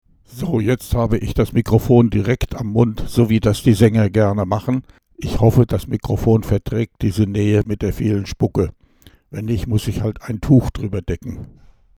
Mikrofon direkt am Mund (mp3)
Die tiefen Töne sind stark angehoben und es gibt Rumpeltöne, die Konsonanten wie "p", "t" und "k" begleiten. Dazu kommen Nebengeräusche, die man "Fauchen" oder "Zischen" nennen kann. Insgesamt klingt die Stimme mit dem Mikrofon am Mund deutlich anders als bei mehr Abstand, und zwar geht es in Richtung "Nuscheln".
Mikro_nah_direkt_am_Mund.mp3